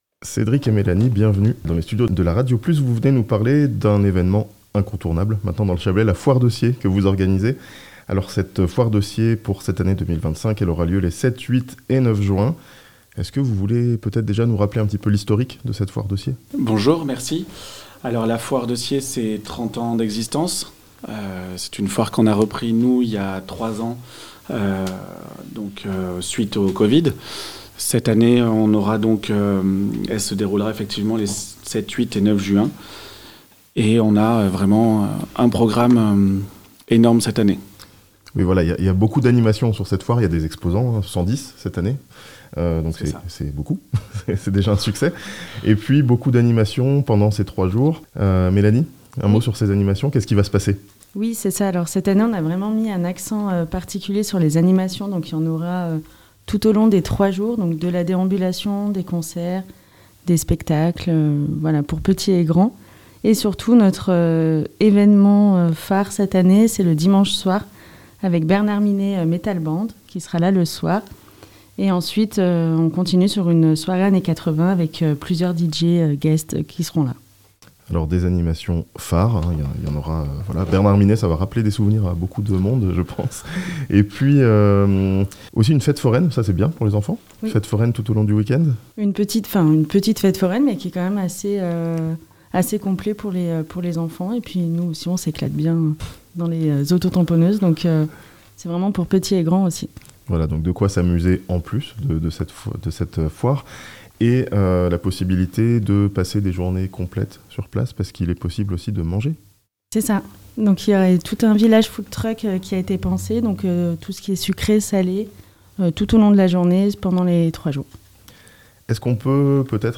La foire de Sciez revient du 7 au 9 juin (interview)